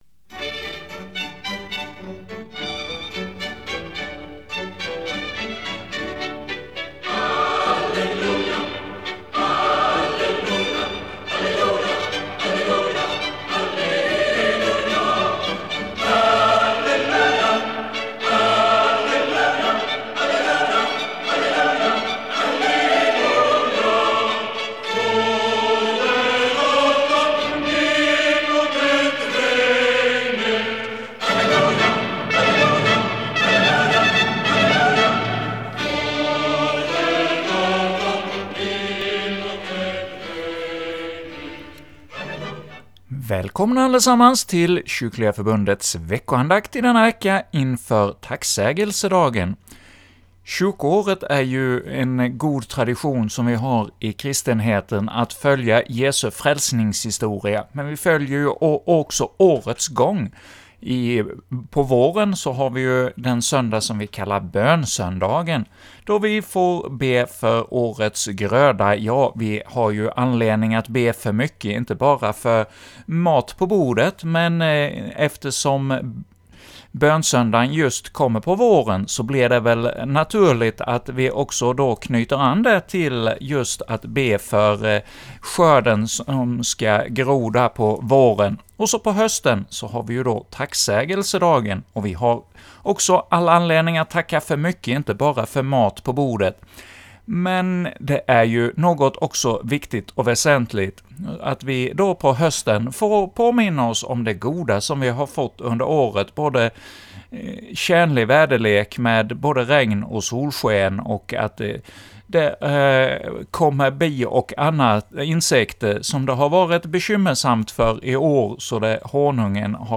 betraktelse inför Tacksägelsedagen